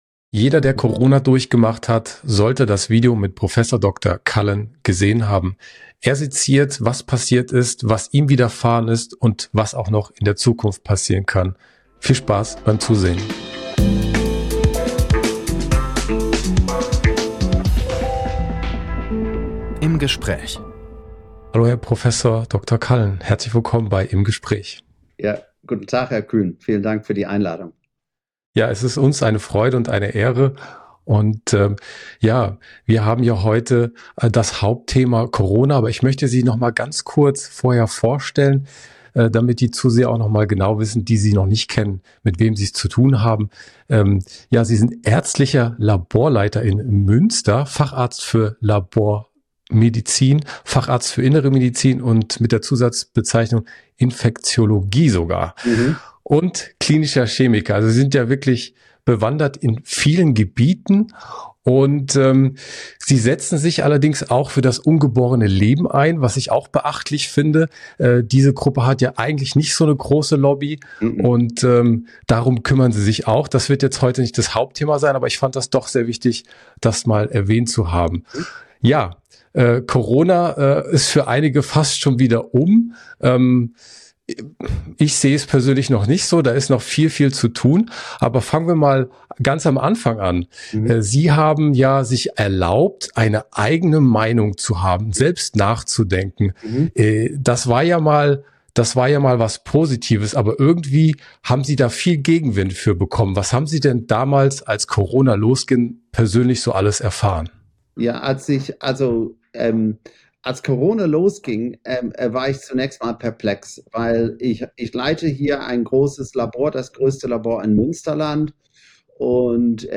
Ein weiterer Schwerpunkt des Gesprächs ist der erschütternde Zustand der medizinischen Kollegenschaft.